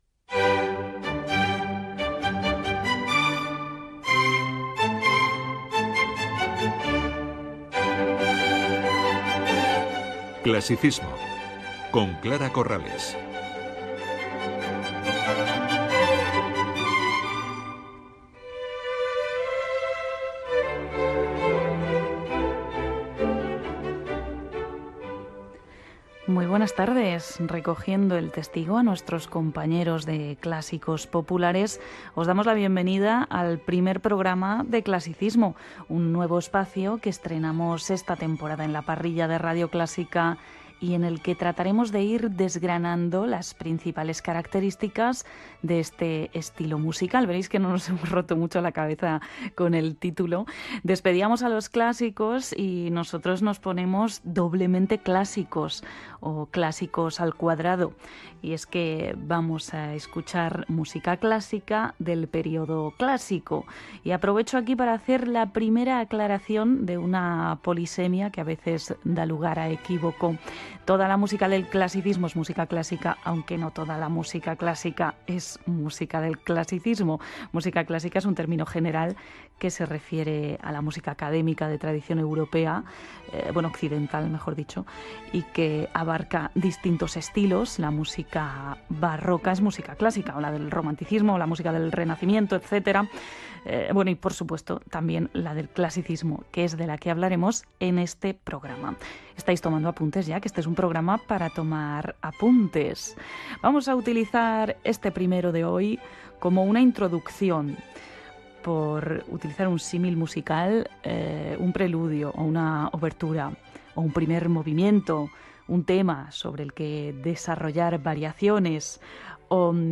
Careta del programa, presentació de la primera edició del programa, aclariment del terme "Classicisme", tema musical, definició de "Classicisme" i tema musical Gènere radiofònic Musical